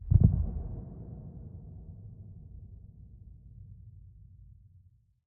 Minecraft Version Minecraft Version snapshot Latest Release | Latest Snapshot snapshot / assets / minecraft / sounds / mob / warden / nearby_close_3.ogg Compare With Compare With Latest Release | Latest Snapshot
nearby_close_3.ogg